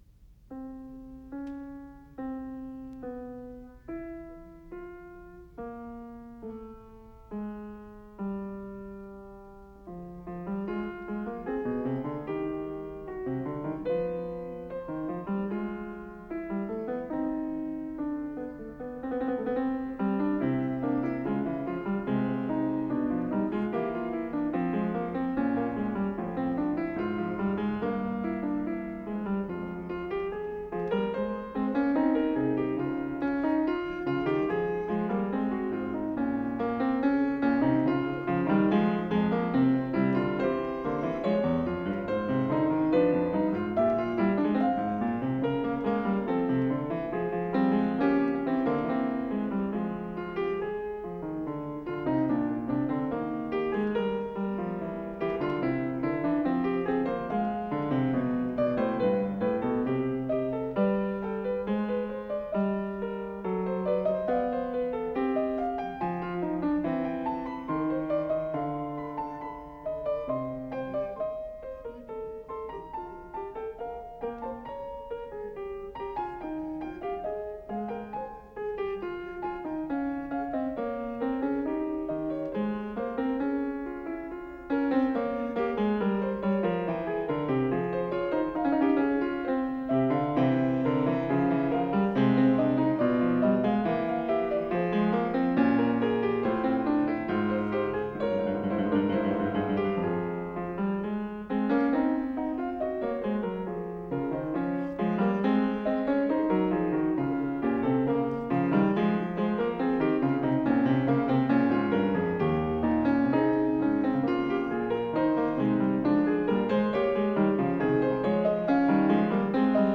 24 Wtc Vol I, Fugue No 12 In F Minor, Bwv857